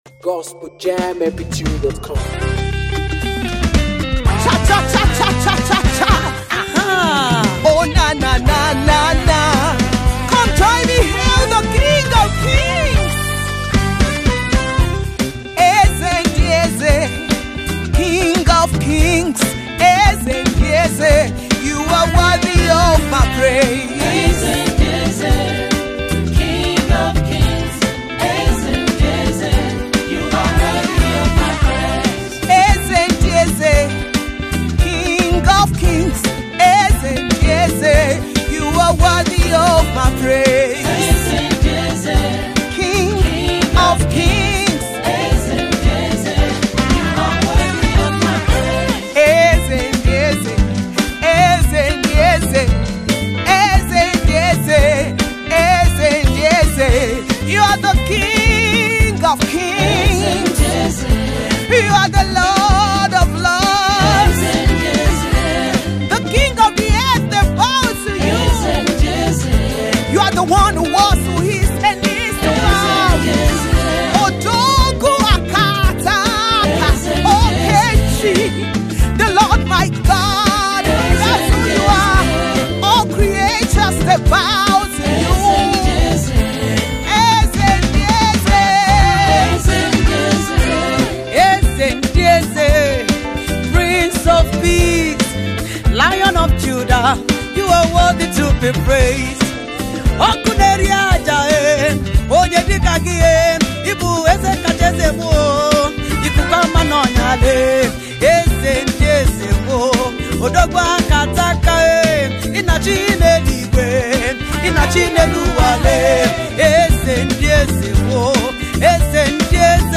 African praisemusic